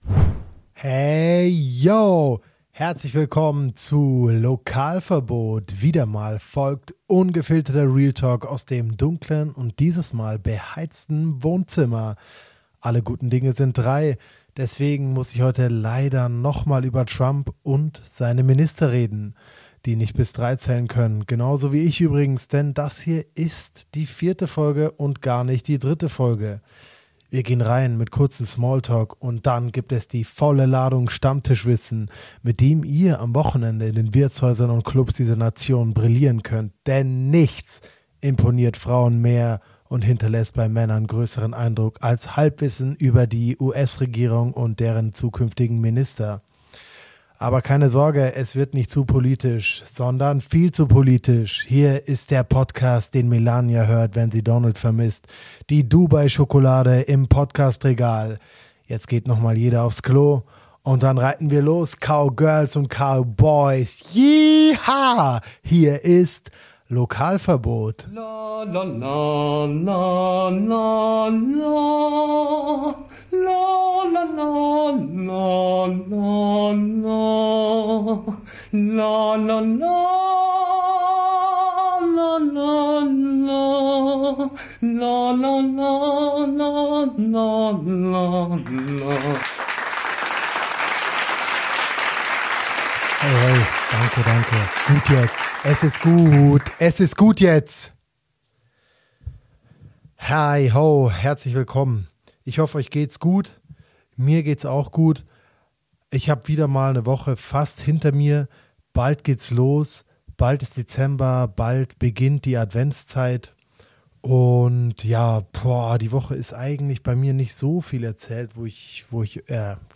Wie anfangs erwähnt, eine eher nachdenkliche Folge, die hoffentlich auch euch da draußen zum Denken anregt.